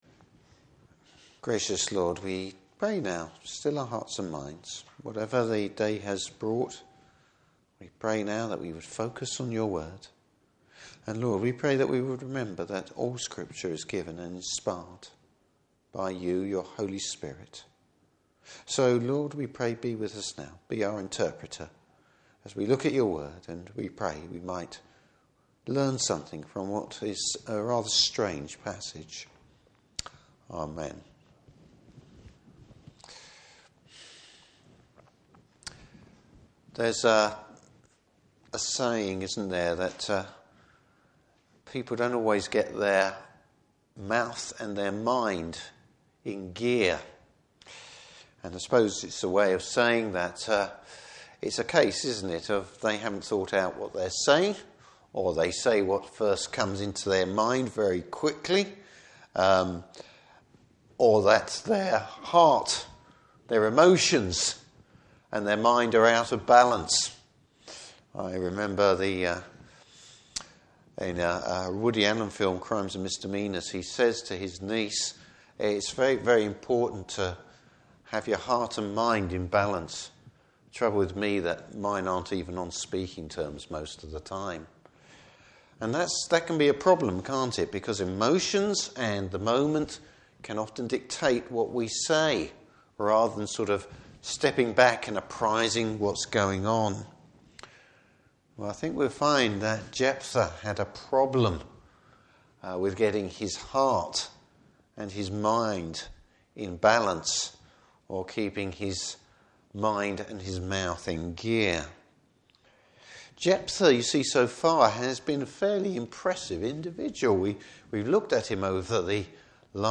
Service Type: Evening Service Jephthah’s very dodgy theology has serious repercussions!